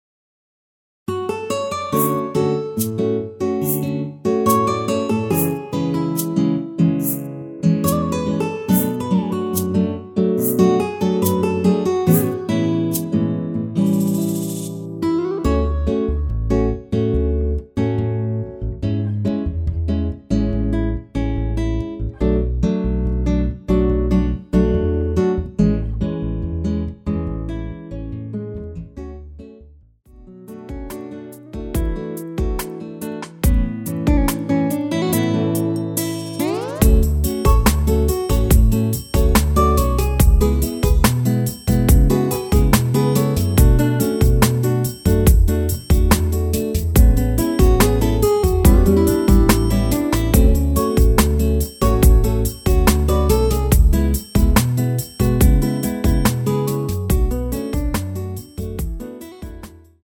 여성분이 부르실만한 축가로 좋은 곡
앞부분30초, 뒷부분30초씩 편집해서 올려 드리고 있습니다.
중간에 음이 끈어지고 다시 나오는 이유는
축가 MR